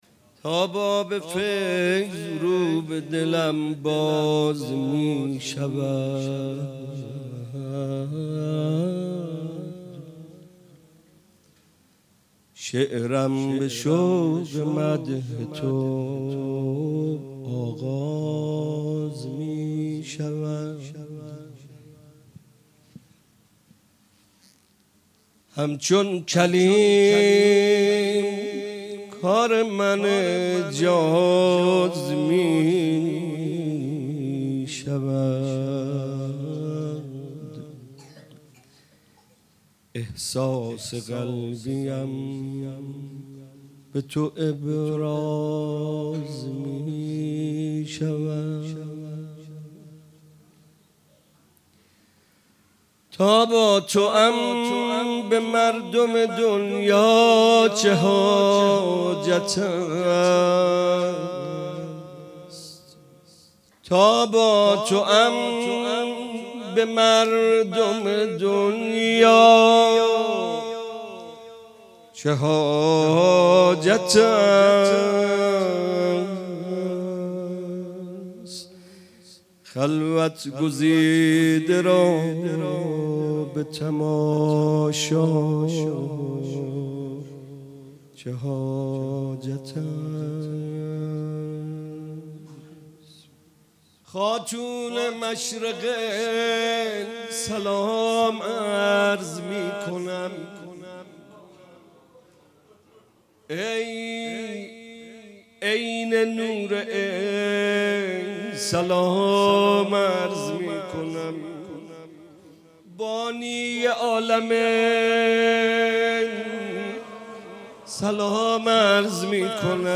ولادت حضرت زینب سلام الله علیها97 - مدح - تا باب فیض رو به دلم